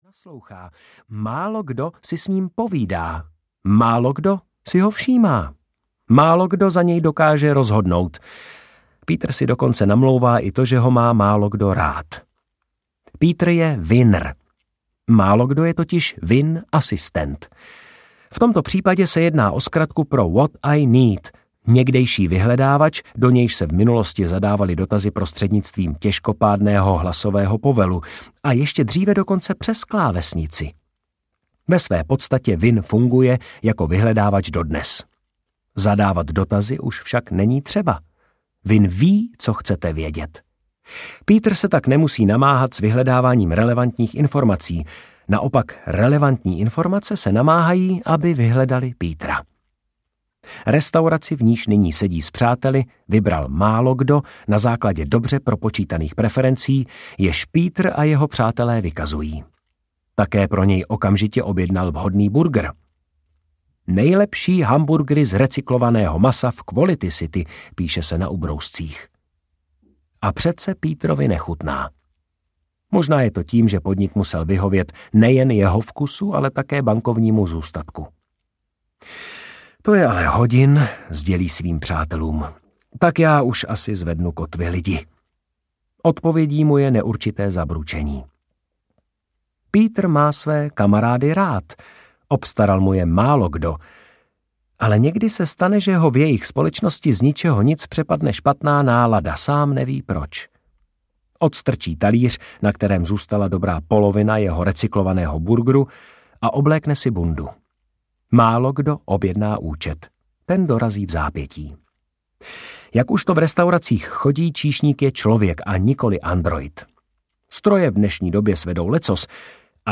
QualityLand audiokniha
Ukázka z knihy
• InterpretLucie Štěpánková, Martin Písařík